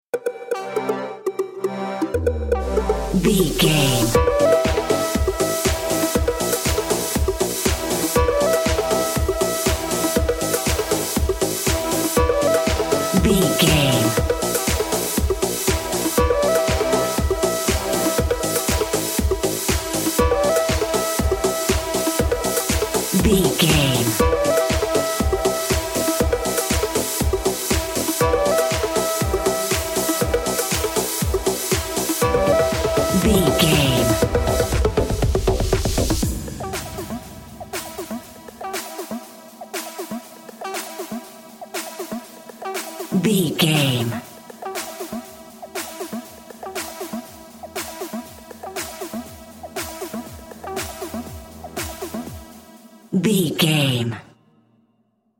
Top 40 Chart Electronic Dance Music 60 Sec.
Aeolian/Minor
groovy
dreamy
futuristic
driving
drum machine
synthesiser
house
techno
trance
instrumentals
synth leads
synth bass
upbeat